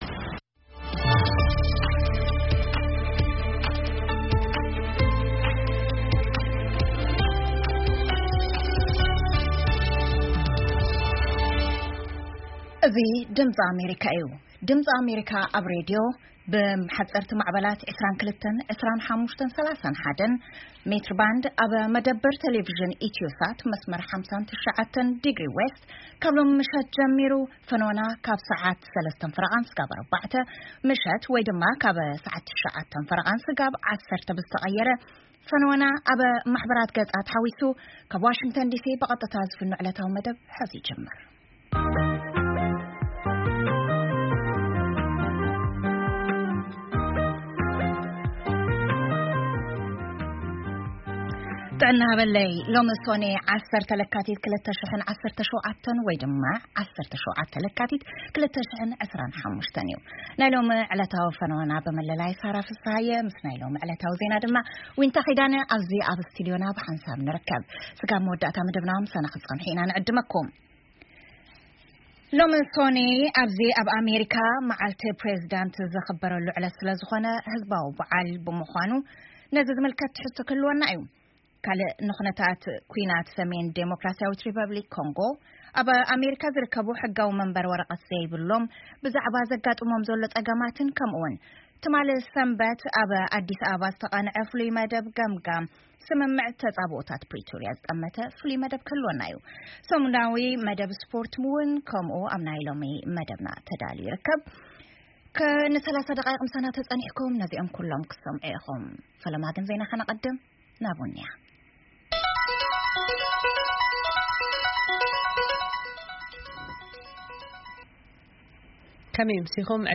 ፈነወ ድምጺ ኣመሪካ ቋንቋ ትግርኛ ዞባዊን ኣህጉራዊን ዜና ሰሙናዊ ዜና ስፖርት መራሕቲ ክልል ትግራይ ንምሉእነት ስምምዕ ተጻብኦታት ፕሪቶሪያ ይጽውዑ